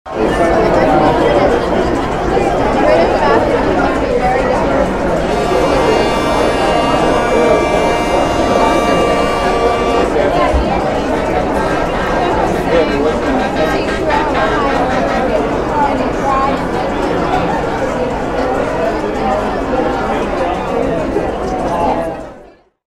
Don’t feel bad if you need to turn it off after a few seconds – it’s rather hard to listen to.
reataurant.mp3